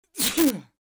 Sneeze Male
Sneeze Male.wav